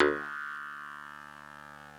genesis_bass_026.wav